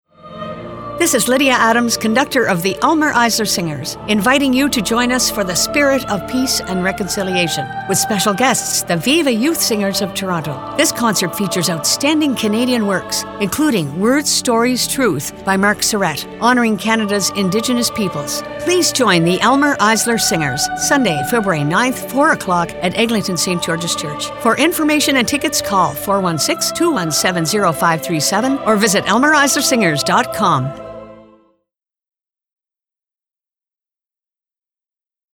Viva Chamber ChoirThe VIVA Chamber Choir is an SATB auditioned choir for singers ages 15 and up, the culmination of the VIVA family of choirs for children, youth, and adults in downtown Toronto.
Eglinton St. George’s United Church